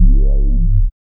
5008R BASS.wav